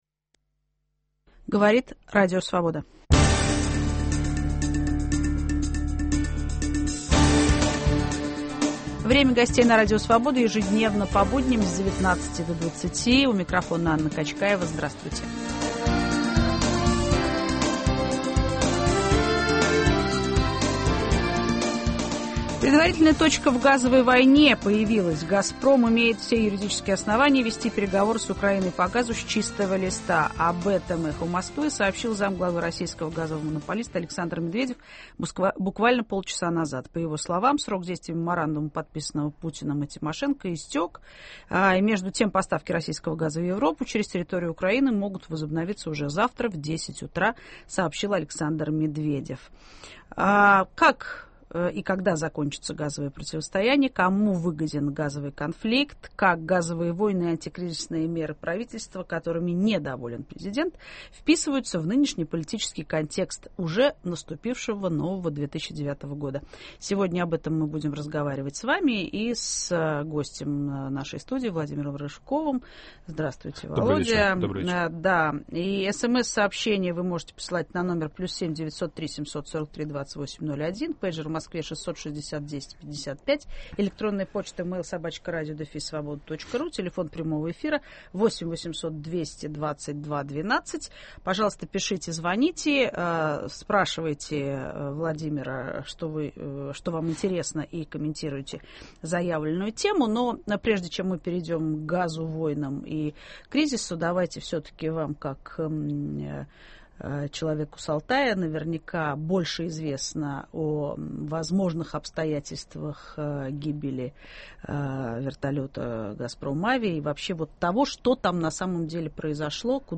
"Газовые войны" и антикризисные меры в политическом контексте обсуждаем вместе с политиком Владимировм Рыжковым